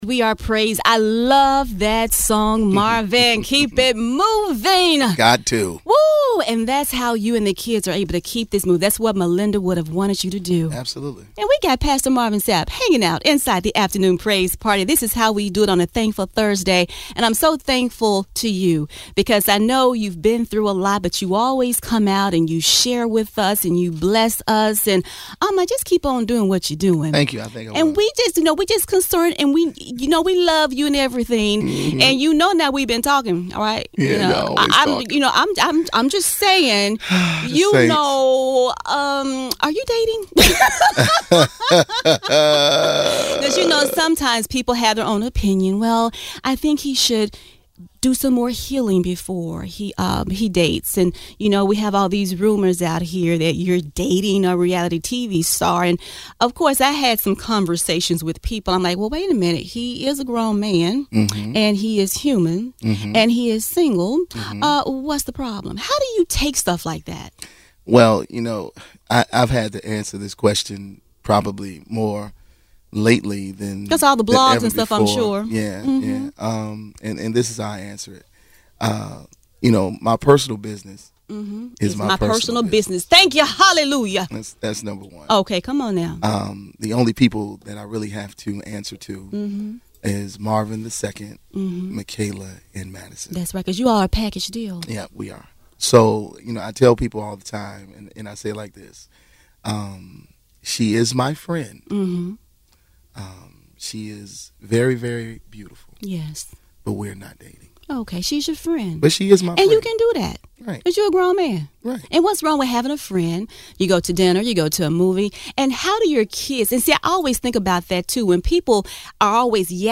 marvin-sapp-interview-part-4.mp3